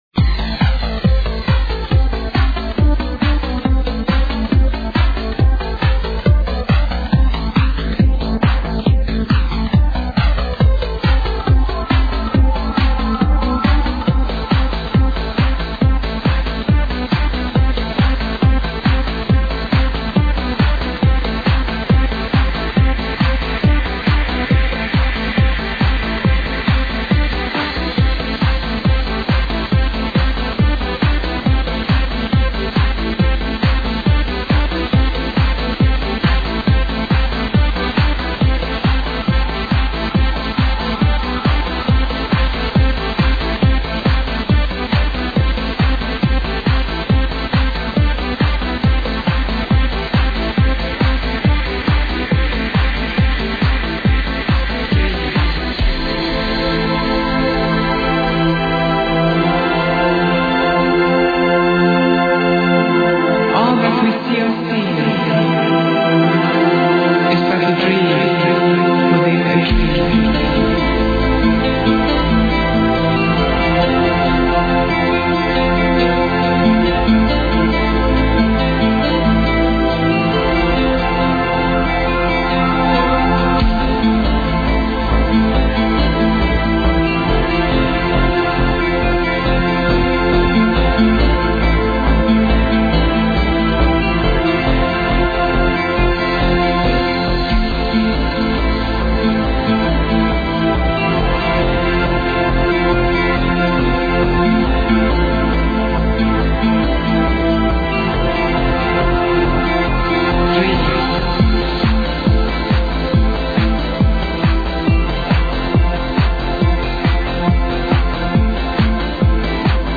it builds to the melody